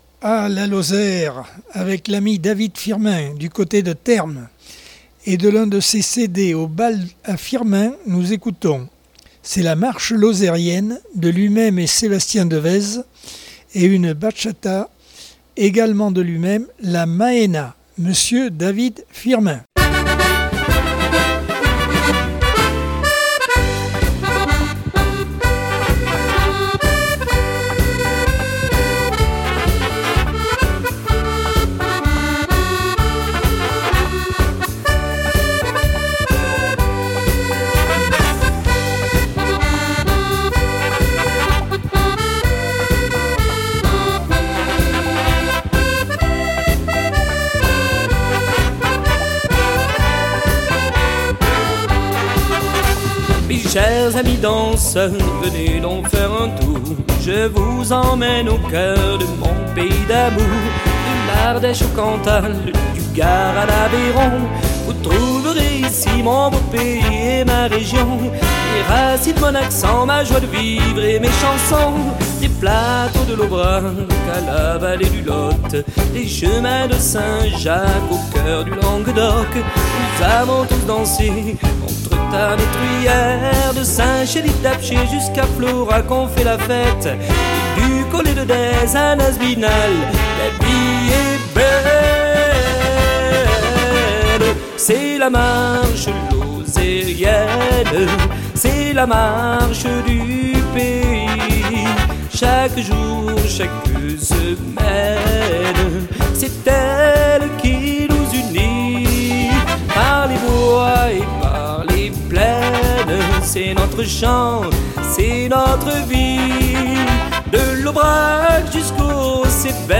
Accordeon 2022 sem 28 bloc 3.